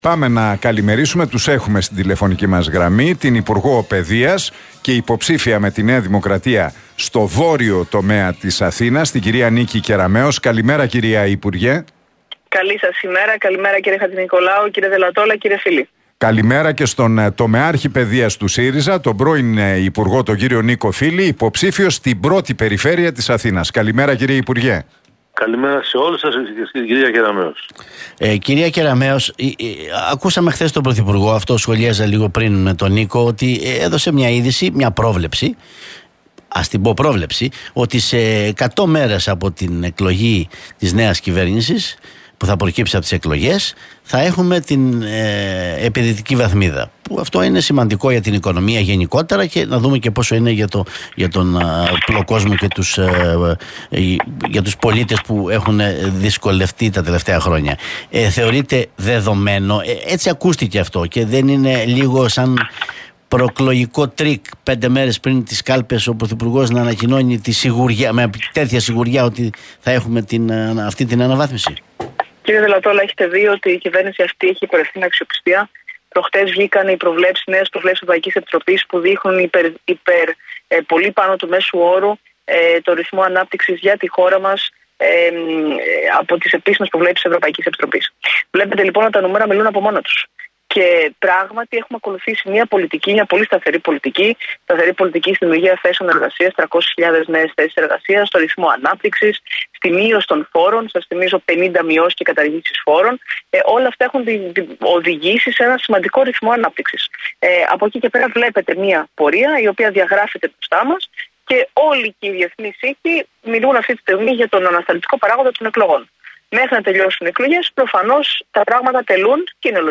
Εκλογές 2023: Debate Κεραμέως - Φίλη στον Realfm 97,8